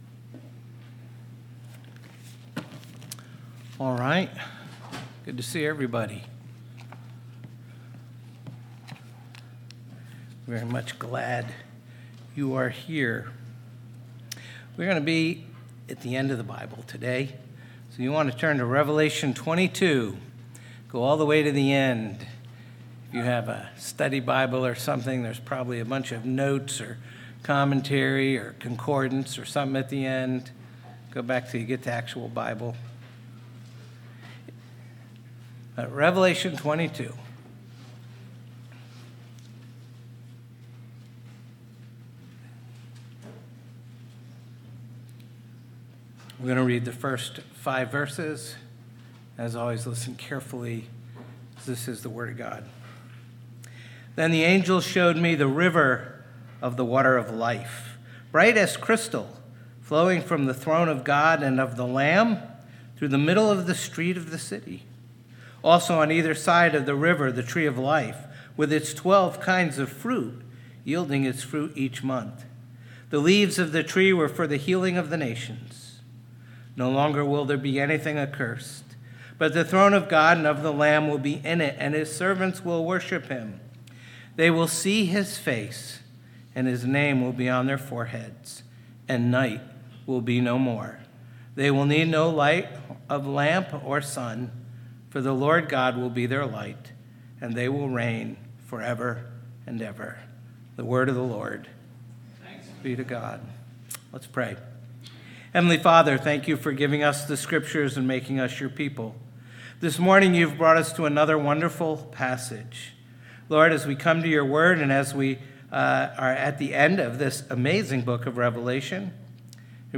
phpc-worship-service-11-2-25.mp3